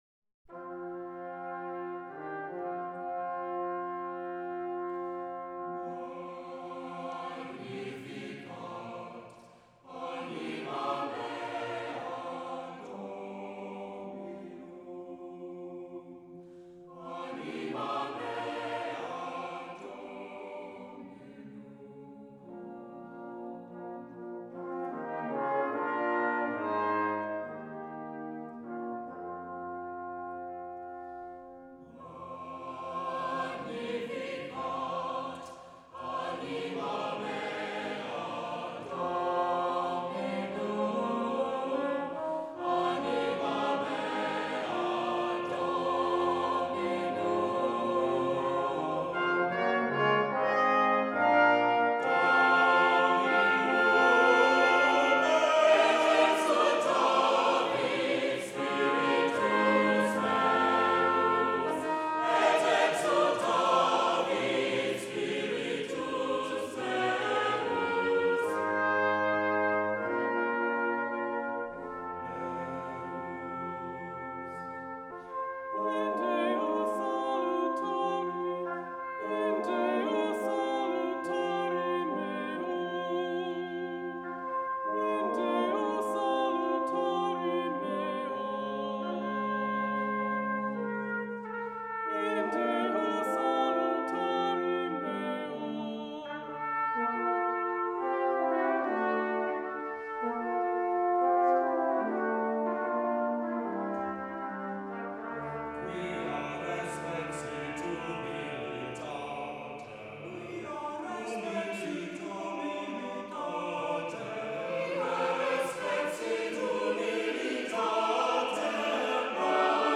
for SATB Chorus, Two Trumpets, and Two Trombones 15 min.